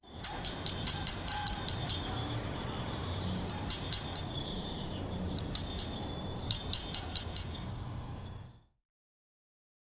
opening a unisef media campaign video with birds sounds in bacground, morning vibe , and rkshaw bell , and opening sound effects cheerful
opening-a-unisef-media-ca-lowikliu.wav